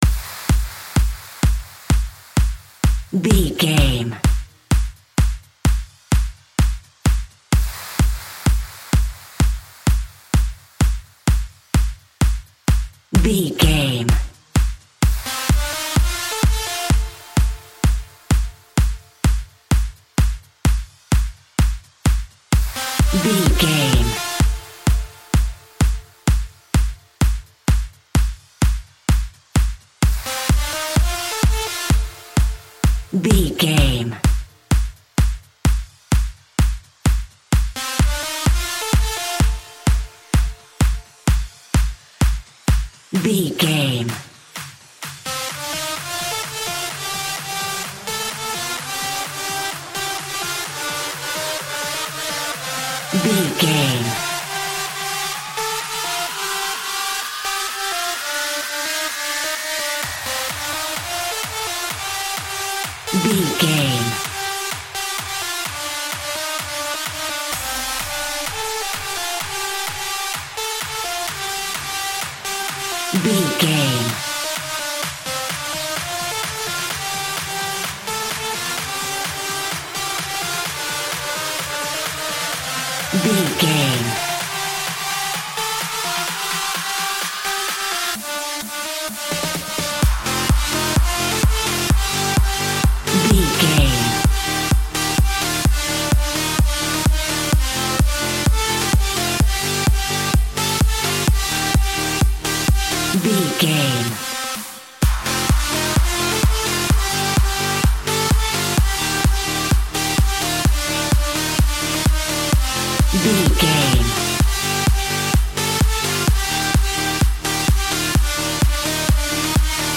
Aeolian/Minor
energetic
hypnotic
uplifting
synthesiser
drum machine
electronic
synth leads
synth bass